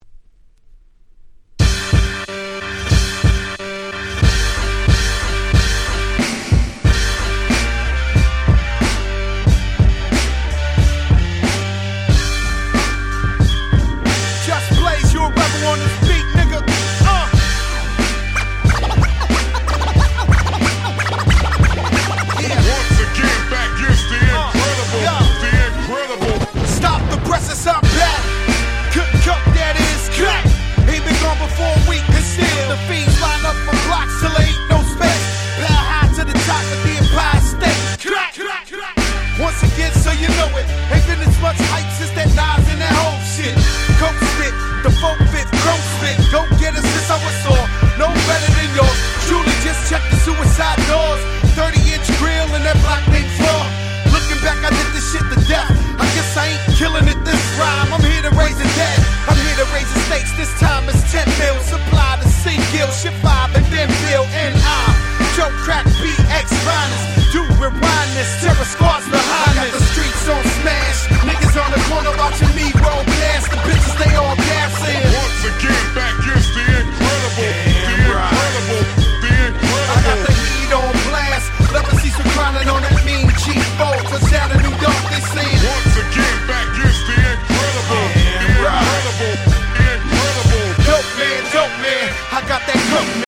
05' Big Hit Hip Hop !!
ファットジョー ハーコー ジャストブレイズ 00's Boom Bap ブーンバップ